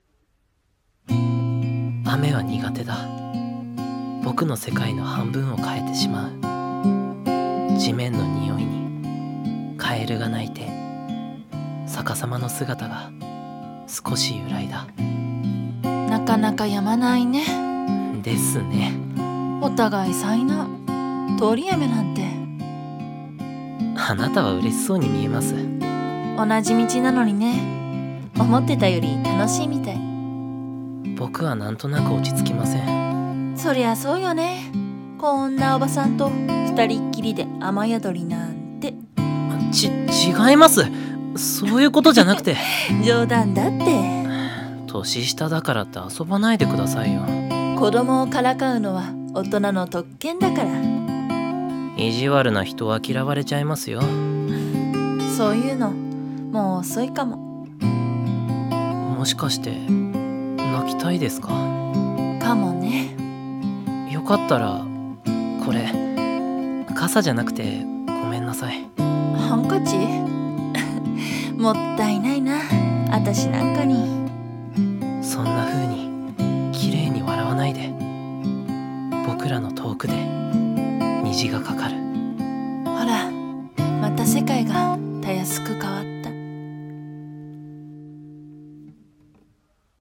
二人声劇【雨宿り】